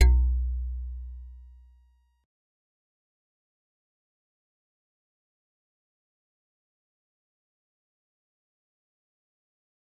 G_Musicbox-B1-pp.wav